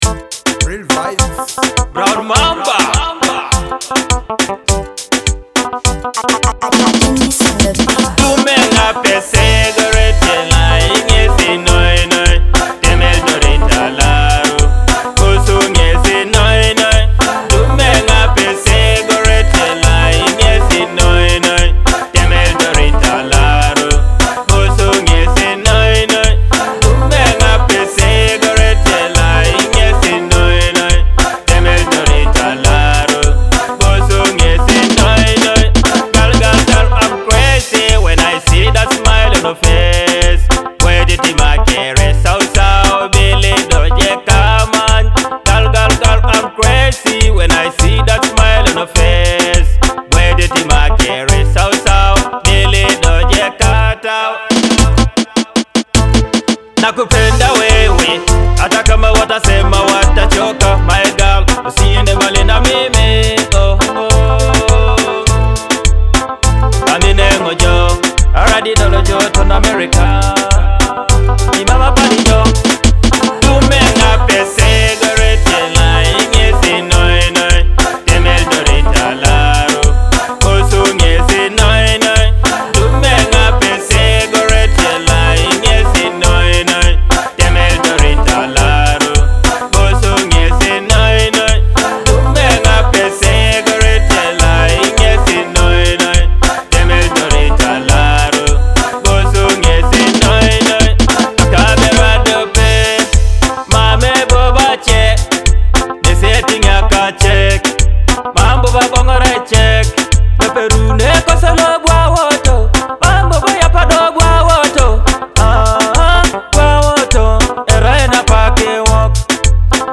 A High-Energy Teso Dancehall Anthem!